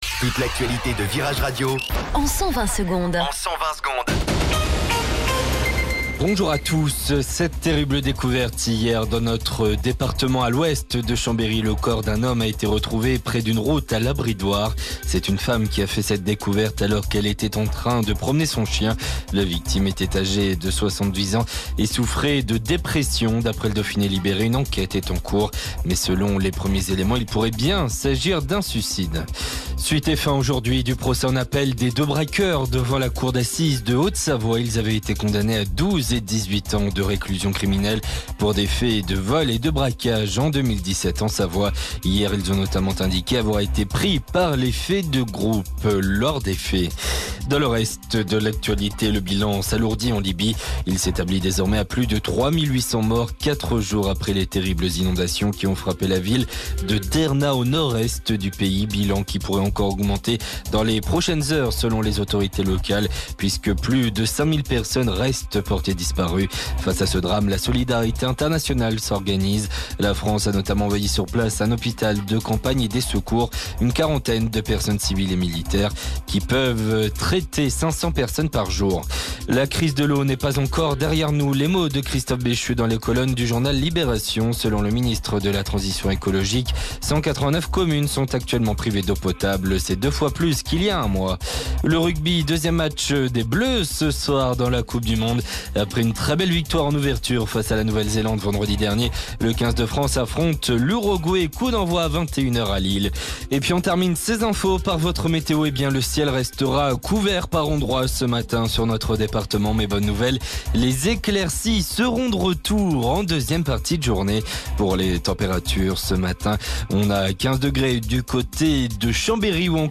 Flash Info Chambéry